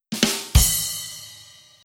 We occasionally post some good jokes, as well as others that deserve the classic Joke Drum Roll and Cymbal crash to denote a bad one.
Drum Roll Cymbal Crash
Stinger.wav